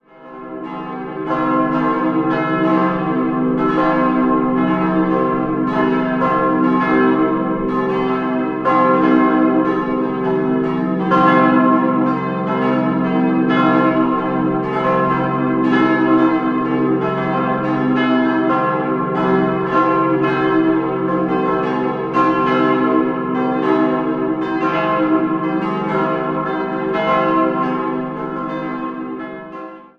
4-stimmiges Salve-Regina-Geläut: c'-e'-g'-a'